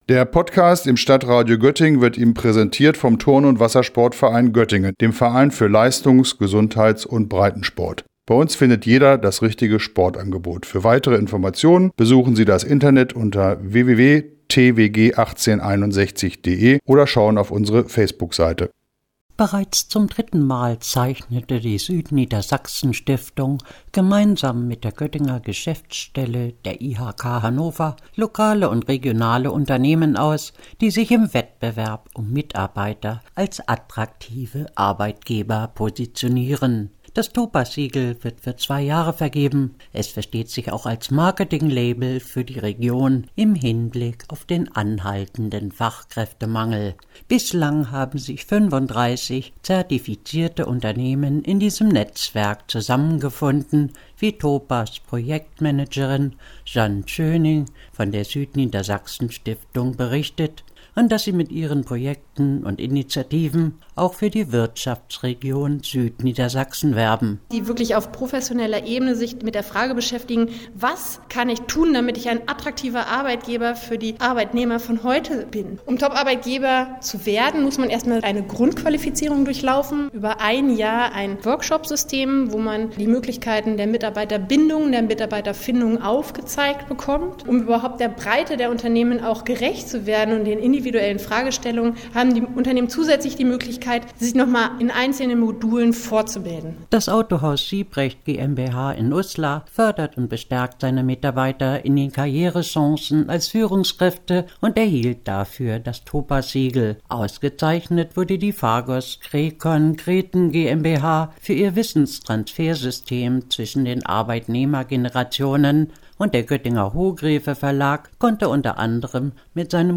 In der Zentrale der Göttinger Werkstätten erhielten die zehn Unternehmen bei einem TOPAs-Netzwerktreffen ihre Auszeichnung.